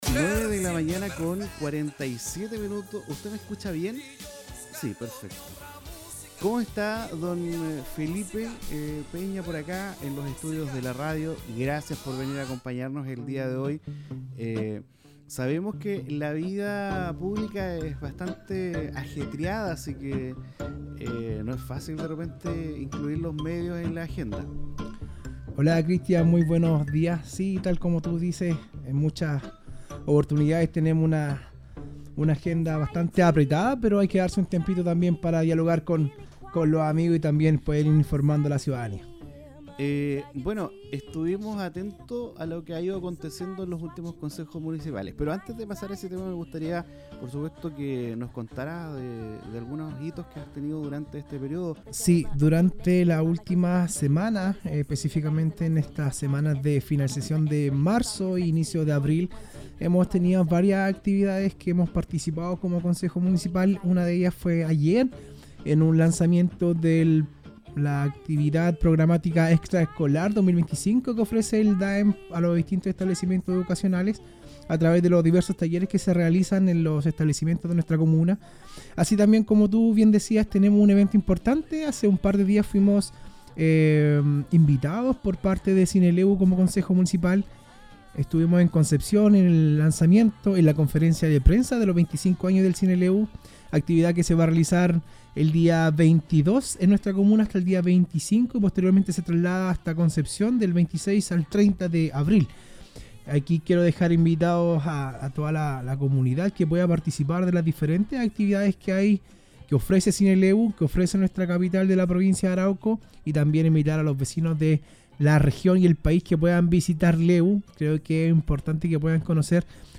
Extracto Entrevista La Ciudad Concejal F. Peña
En entrevista en radio La Ciudad dio a conocer la iniciativa que busca beneficiar a los adultos mayores y personas con discapacidad, ademas se mostro conforme por la recepción de esta propuesta, agradeciendo a la disposición administración municipal a evaluar lo presentado, y además agradeció tambien por apoyo recibido de sus pares concejales.
extracto_entrevista_la_ciudad_concejal_f__pena.mp3